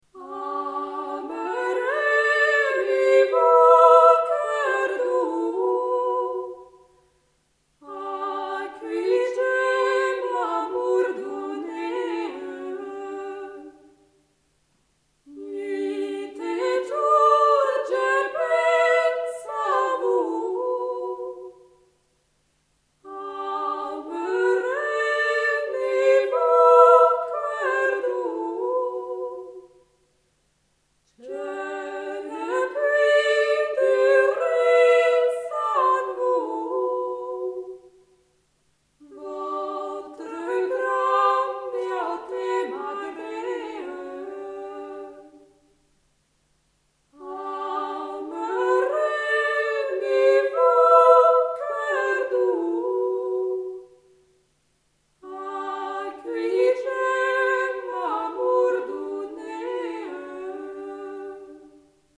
Rondeau